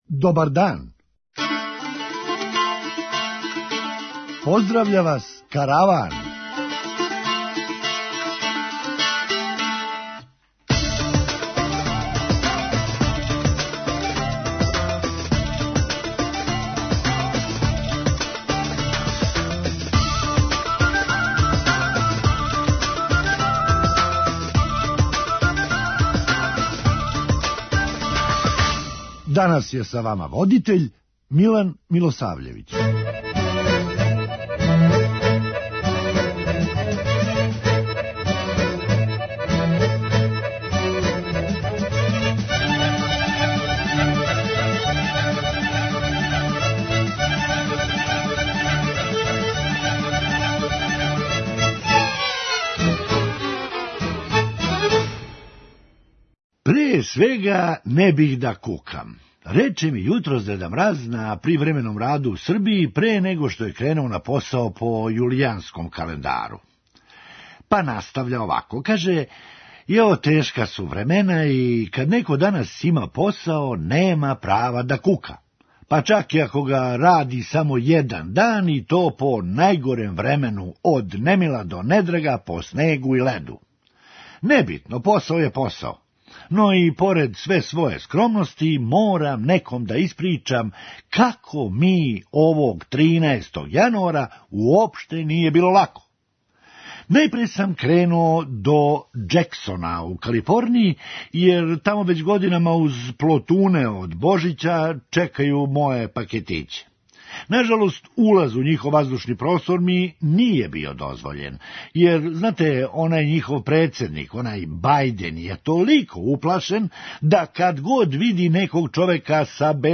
Хумористичка емисија
Они завидни и злонамерни покушавају да боравак на зимовању прогласе ''Данима Зрењанина на Копаонику''. преузми : 9.16 MB Караван Autor: Забавна редакција Радио Бeограда 1 Караван се креће ка својој дестинацији већ више од 50 година, увек добро натоварен актуелним хумором и изворним народним песмама.